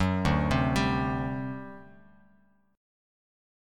D11 Chord
Listen to D11 strummed